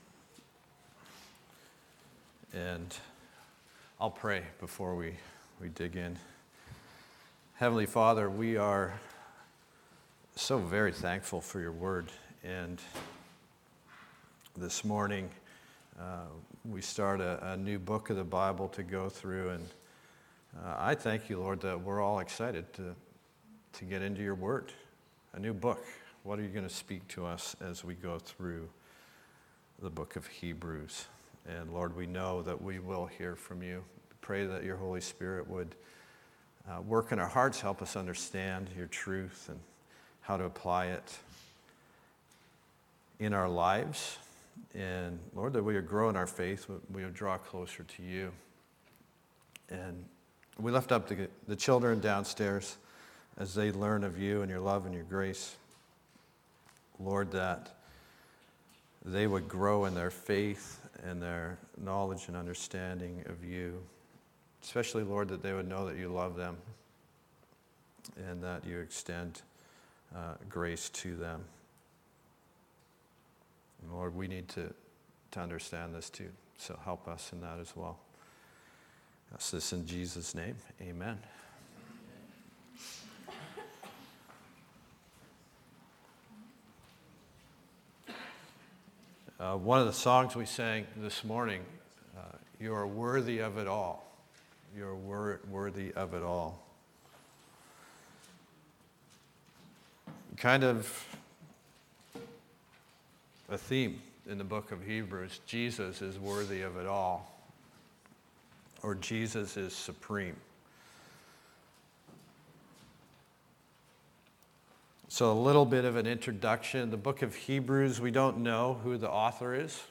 Sermons | Calvary Chapel Comox Valley